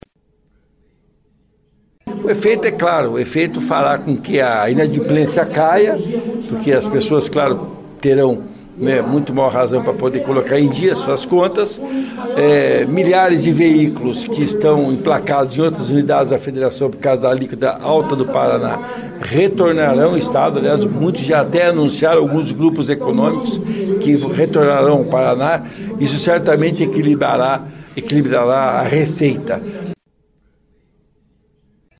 Mesmo sem a chegada oficial do projeto, deputados estaduais de oposição e situação comentaram sobre a redução de 45% durante sessão ordinária na tarde desta segunda-feira (25).